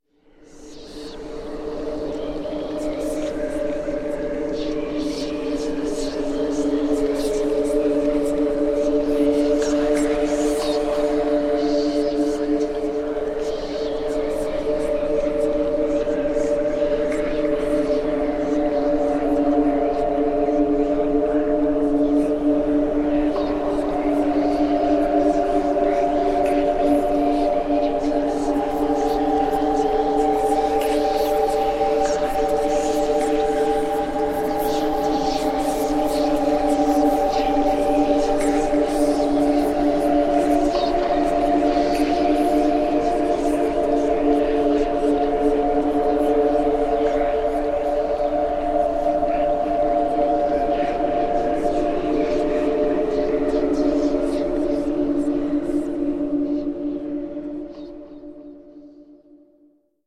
Ветер и шепот призраков (когда гуляешь по кладбищу после полуночи в полной темноте)
veter-i-shepot-prizrakov-kogda-guliaesh-po-kladbishchu-posle-polunochi-v-polnoi-temnote.mp3